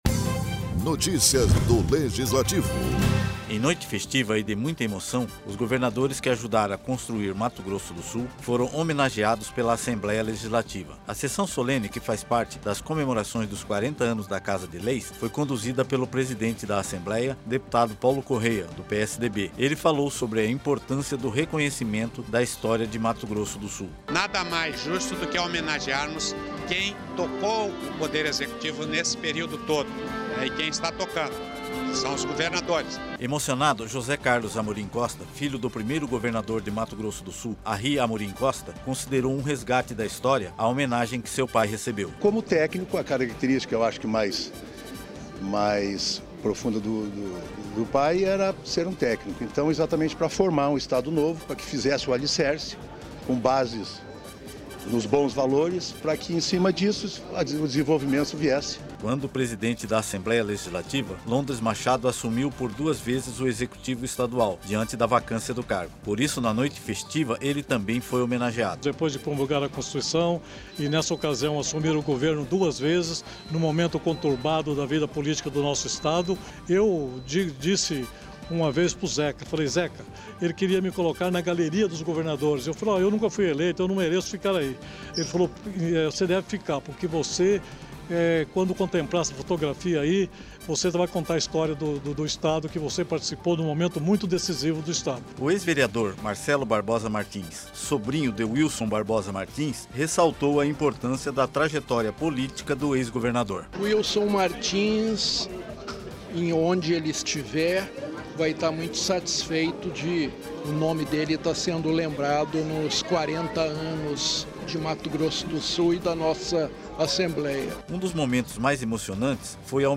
Ex-governadores recebem homenagem em sessão solene na ALMS
Locução e Produção: